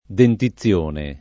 [ denti ZZL1 ne ]